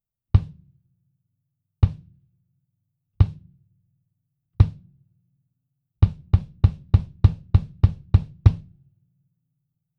もちもちサウンドですね！
実にふくよかな良いバスドラムの音が録れます。
EQ等は一切していません。
バスドラム　OUT
112ドラムキックアウト.wav